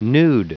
Prononciation du mot nude en anglais (fichier audio)
Prononciation du mot : nude